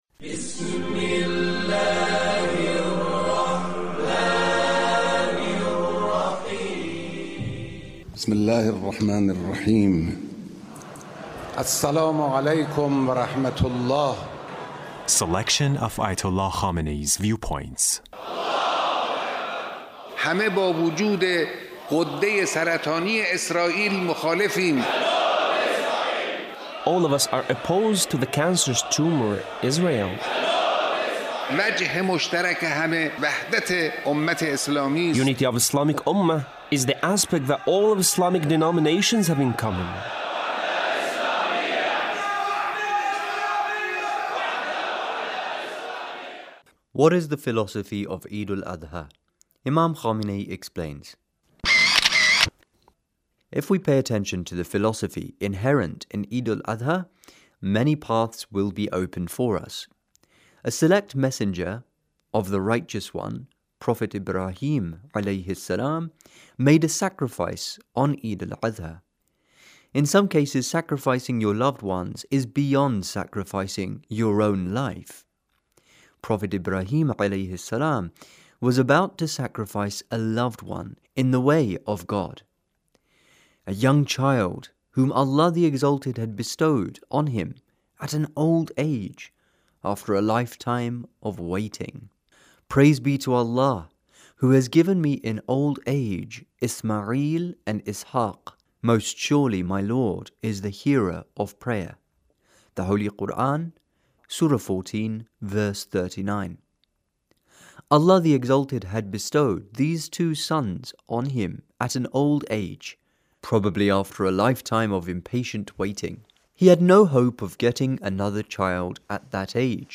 The Leader's speech on Eid al adha